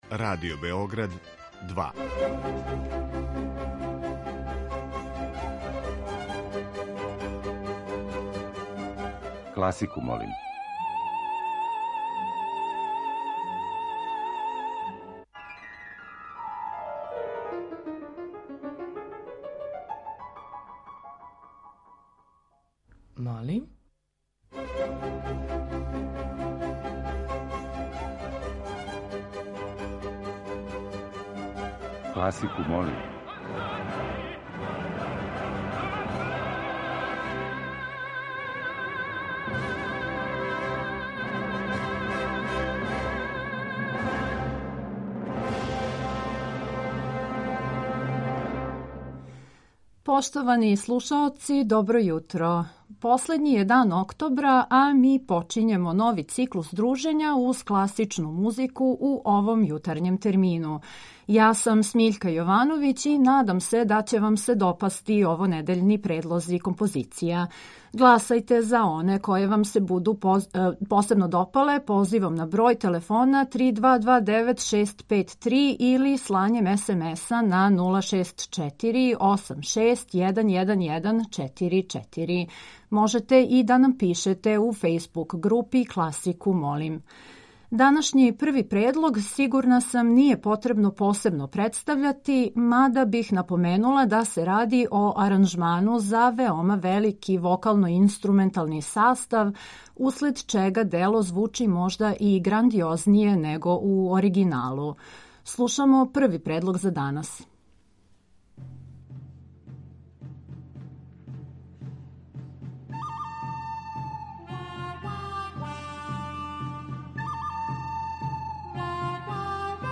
И ове седмице, од понедељка до четвртка емитоваћемо разноврсне предлоге композиција класичне музике.
Стилски и жанровски разноврсни циклус намењен и широком кругу слушалаца који од понедељка до четвртка гласају за топ листу недеље.